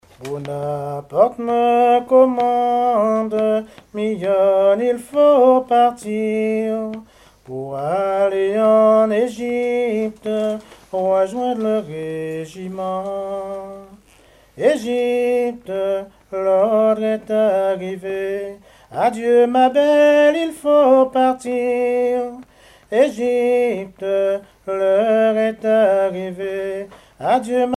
Genre strophique
chansons anciennes recueillies en Guadeloupe
Pièce musicale inédite